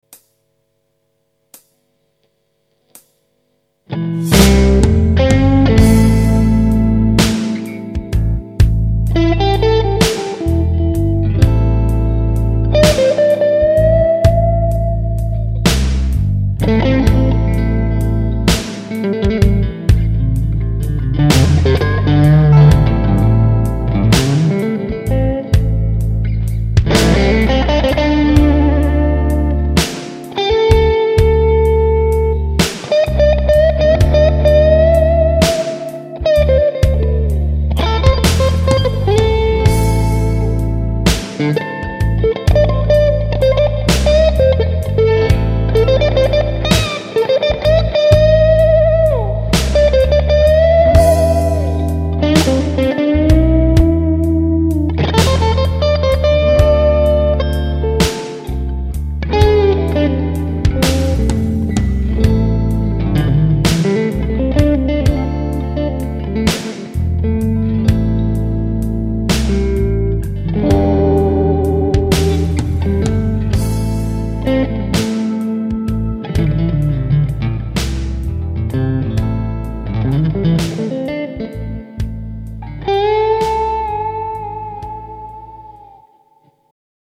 Nothin' but the blues
Hyvää tummaa pehmeätä kitaraa ja soitossa herkkää minimaalista tunnebluesia.